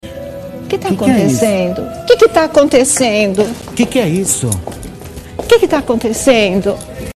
Sonia Abrão desesperada em seu programa: O que tá acontecendo, o que tá acontecendo?